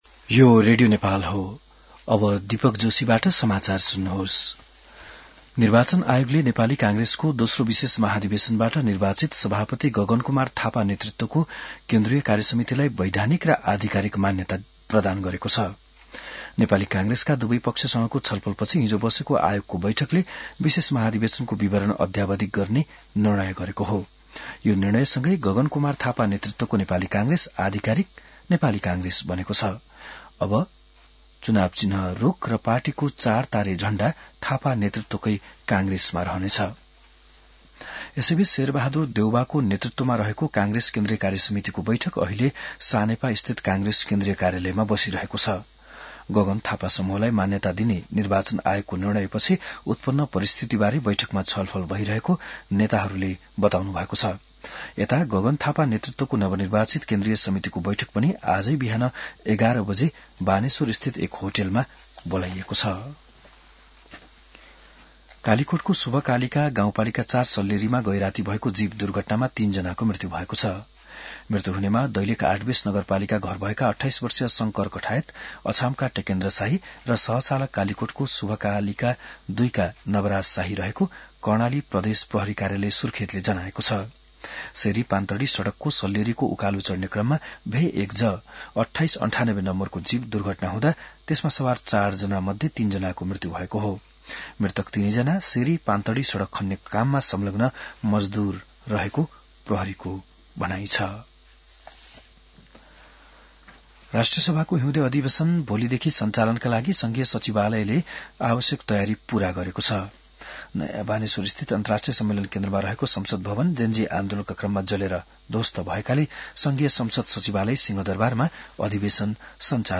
An online outlet of Nepal's national radio broadcaster
बिहान १० बजेको नेपाली समाचार : ३ माघ , २०८२